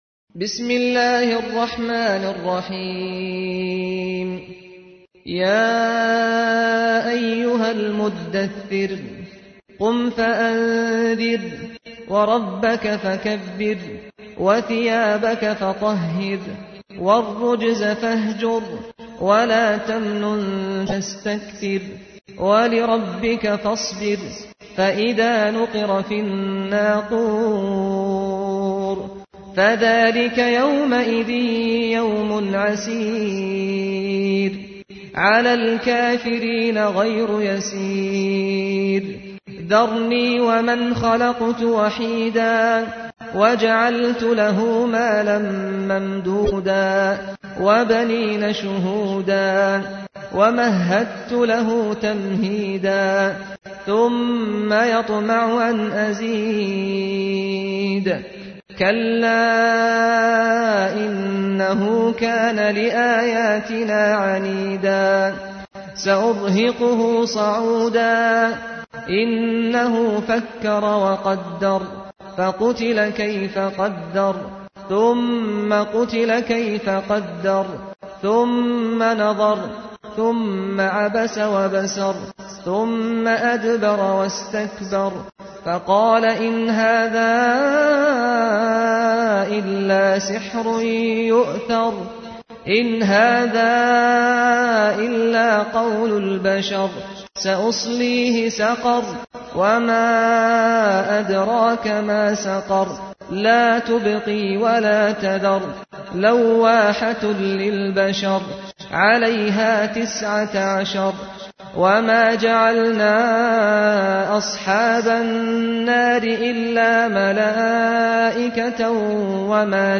تحميل : 74. سورة المدثر / القارئ سعد الغامدي / القرآن الكريم / موقع يا حسين